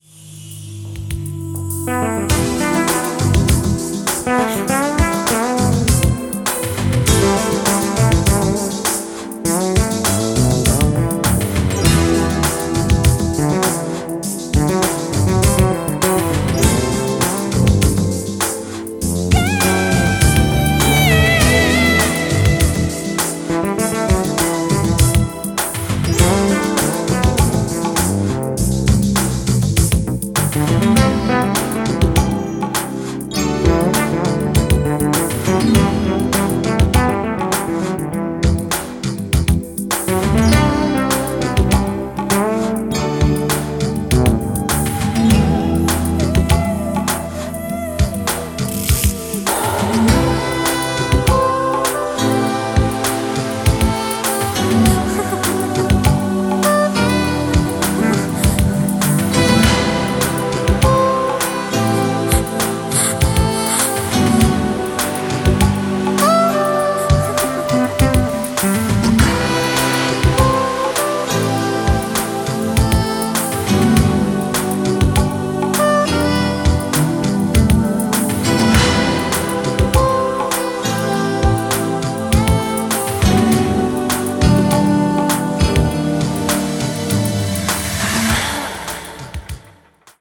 ジャンル(スタイル) NU DISCO / BALEARIC / CLASSIC HOUSE